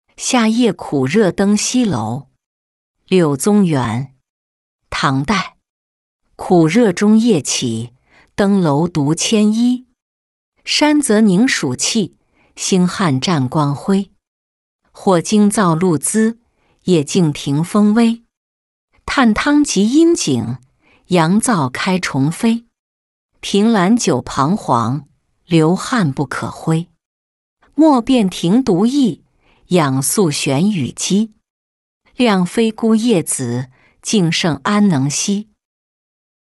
夏夜苦热登西楼-音频朗读